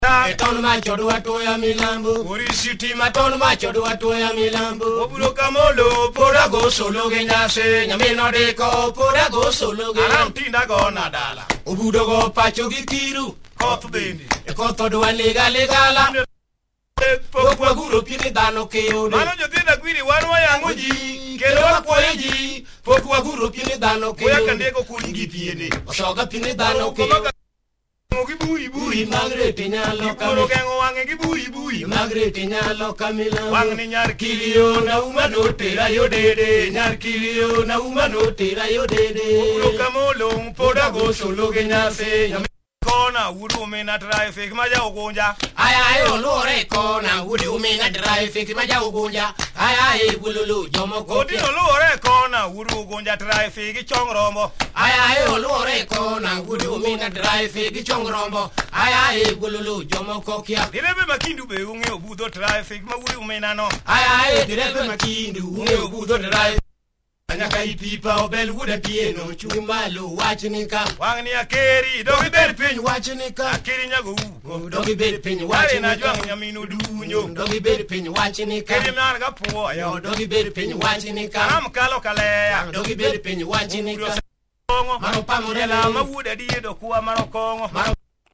Traditional Luo vocal choir